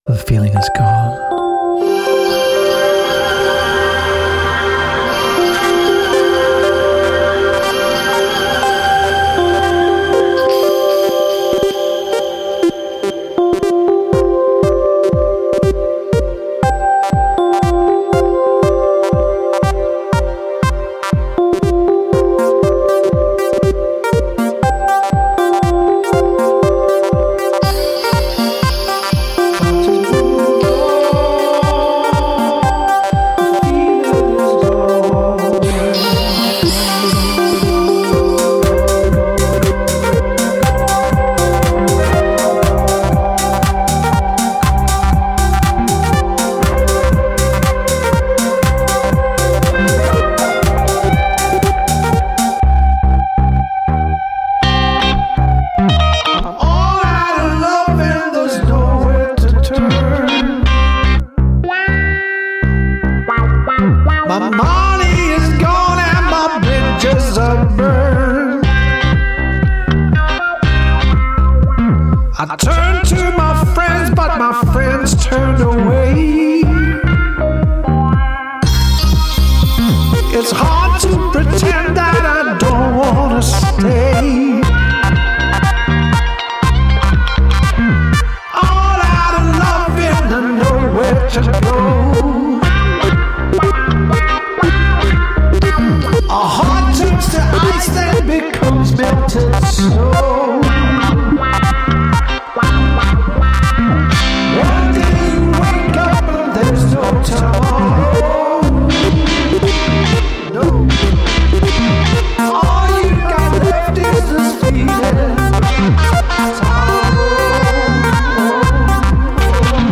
Techno-pop-dance-blues fusion...From one crazy mixed up Guy;-D